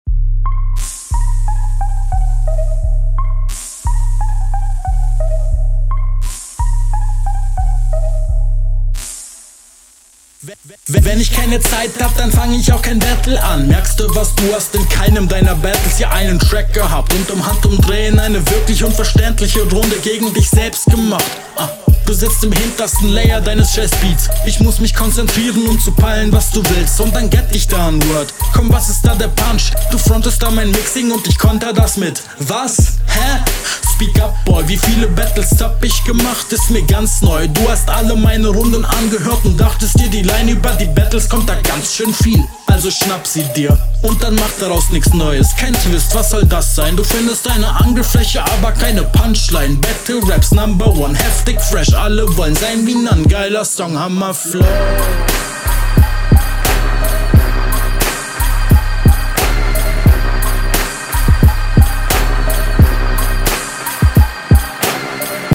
Yo, Stimme ist bisi laut aber immerhin versteht man dich sehr gut und dein Flow …